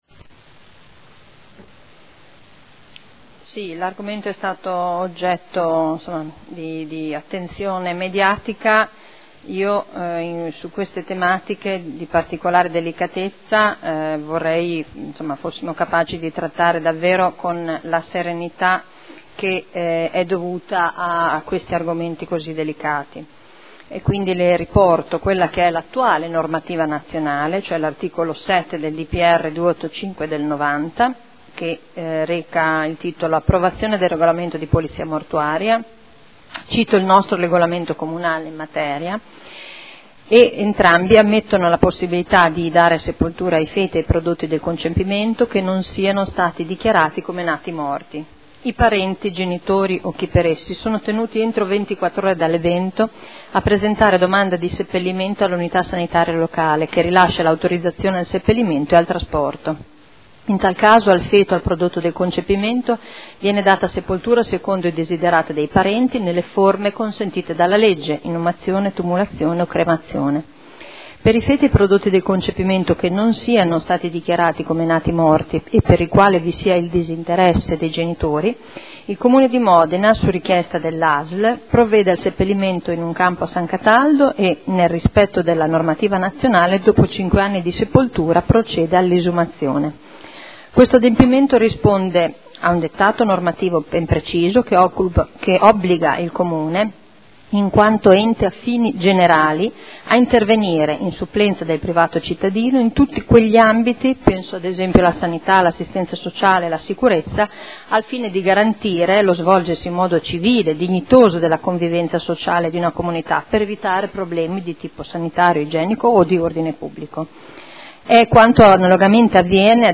Simona Arletti — Sito Audio Consiglio Comunale
Interrogazione del gruppo consiliare SEL avente per oggetto: “Il cimitero dei feti” – Primo firmatario consigliere Ricci. Risposta dell'assessore